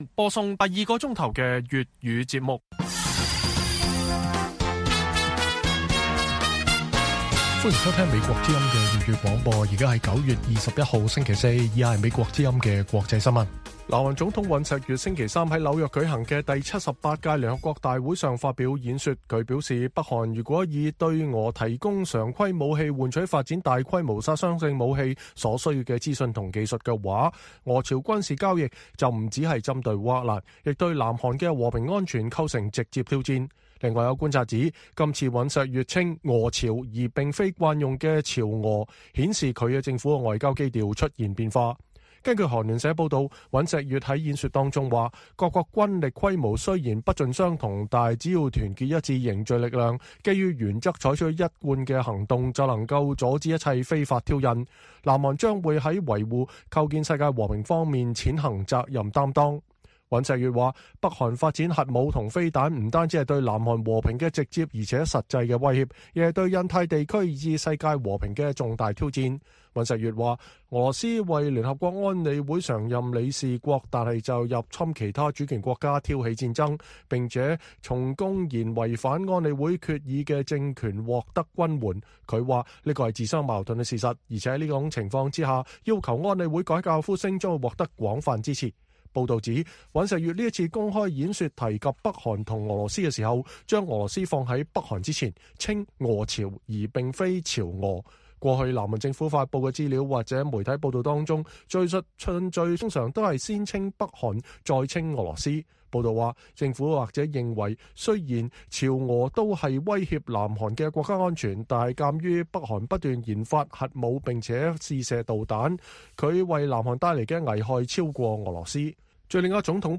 粵語新聞 晚上10-11點: 南韓總統尹錫悅指俄朝軍事交易直接威嚇南韓的和平安全